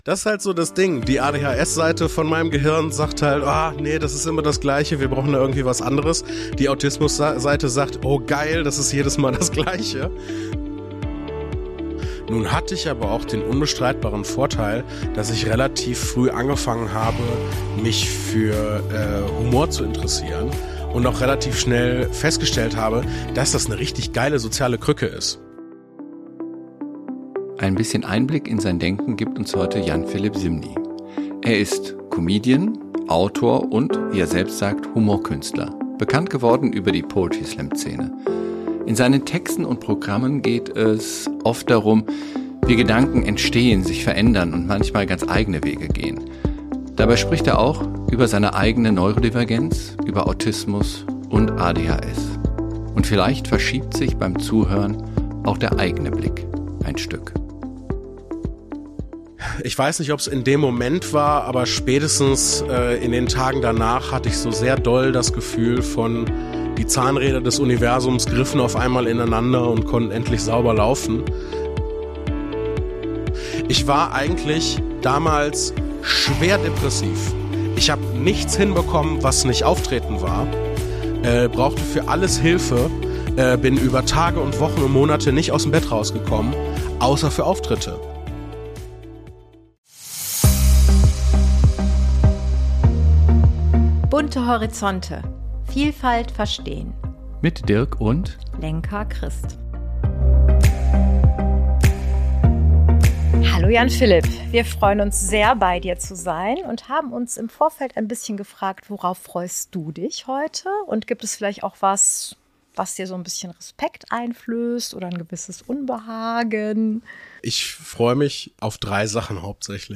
In dieser Folge sprechen wir mit Jan Philipp Zymny – Comedian, Autor und Humorkünstler – über Neurodivergenz, über Autismus und ADHS und darüber, was das ganz konkret im Alltag und auf der Bühne bedeuten kann. Das Gespräch wird dabei immer wieder persönlich. Jan Philipp teilt eigene Erfahrungen, beschreibt Situationen, die ihn geprägt haben, und macht sichtbar, wie unterschiedlich Perspektiven sein können.